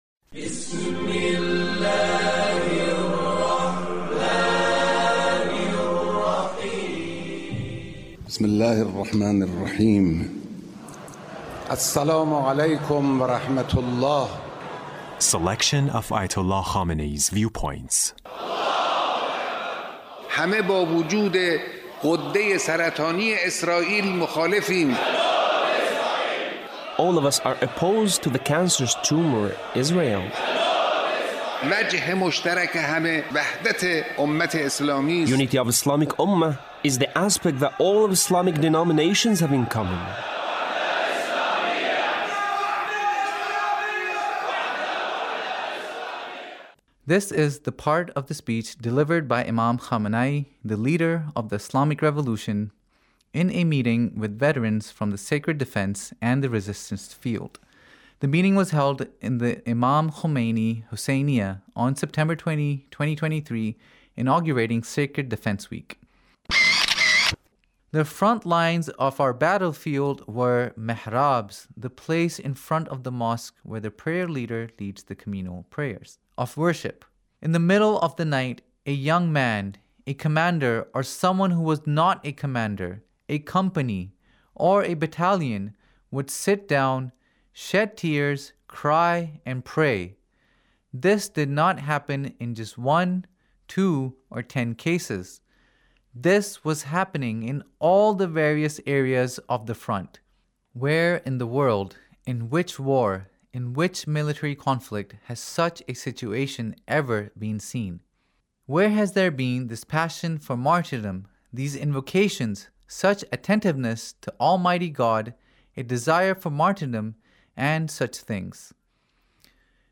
Leader's Speech about Sacred defense